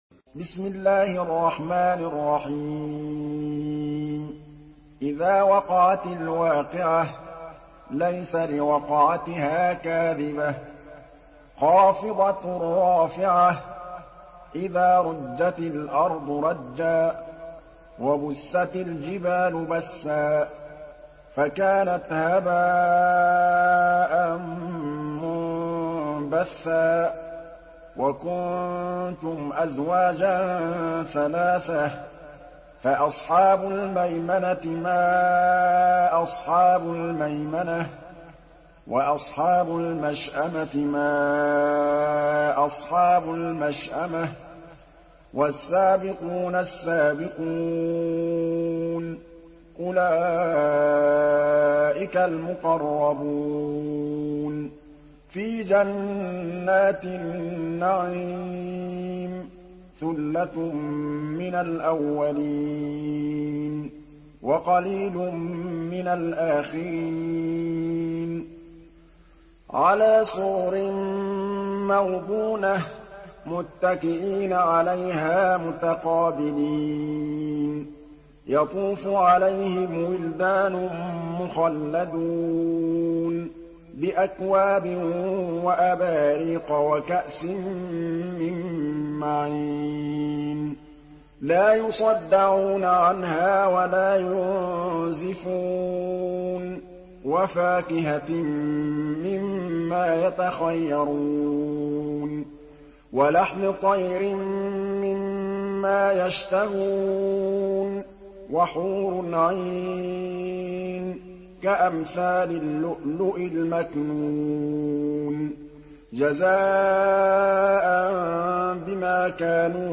Surah আল-ওয়াক্বি‘আহ MP3 by Muhammad Mahmood Al Tablawi in Hafs An Asim narration.
Murattal